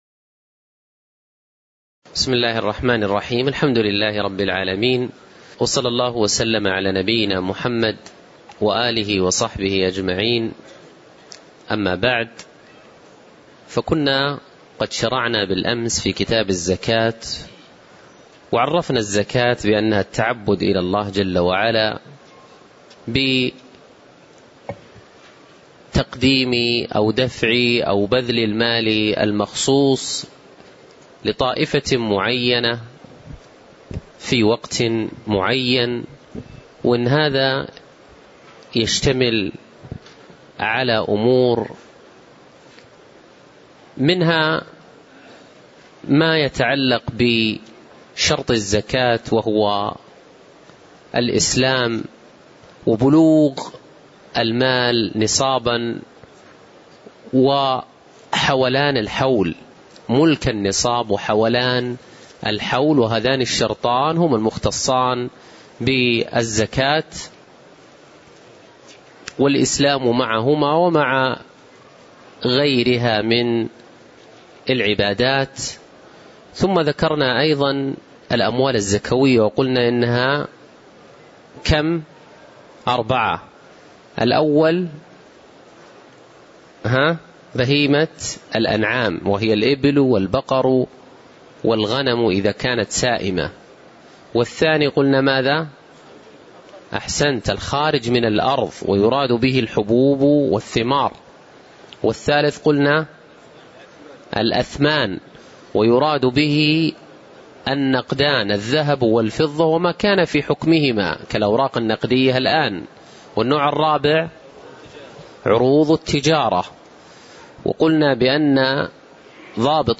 تاريخ النشر ١٧ شوال ١٤٣٧ هـ المكان: المسجد النبوي الشيخ